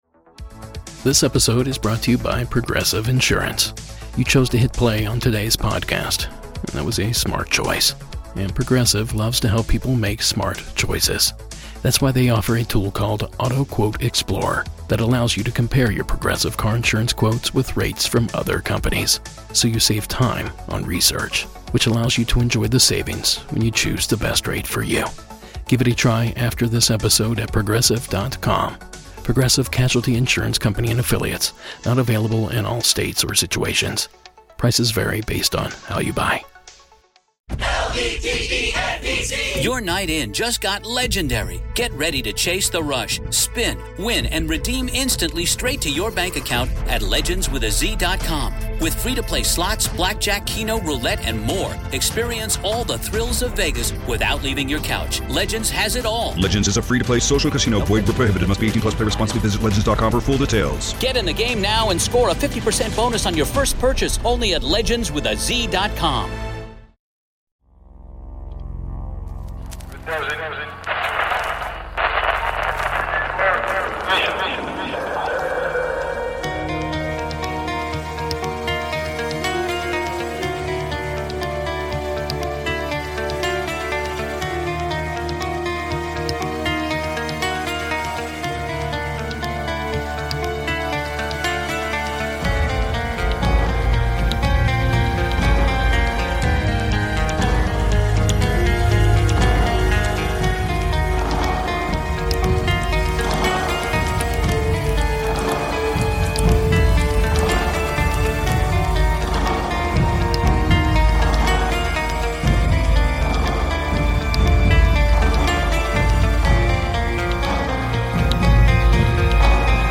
Witnesses of bigfoot, sasquatch, ufo's, aliens, ghosts and an array of other creatures from the paranormal and cryptozoology realm detail their encounters.